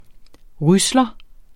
Udtale [ ˈʁyslʌ ]